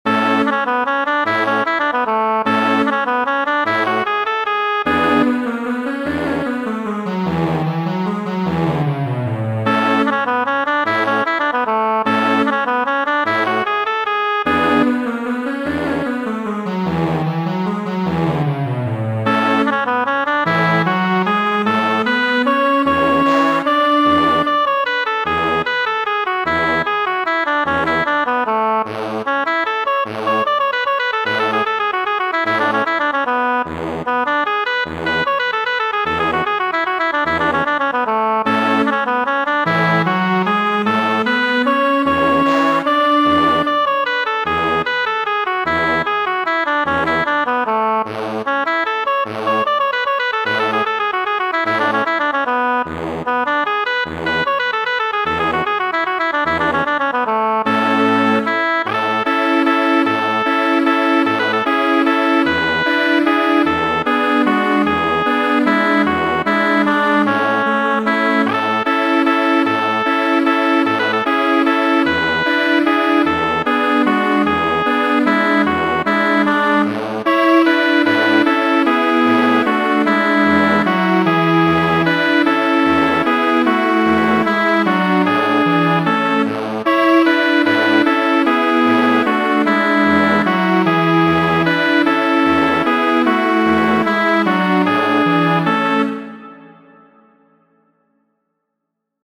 Muzika vespero.-
Ĥotoj kastilajn masimilas al tiuj de aliaj partoj de Hispanio, kiel Aragono aŭ Mursjo, pri tio, ke la ritmo estas pli malrapida, kaj la muzikiloj malpli nombraj.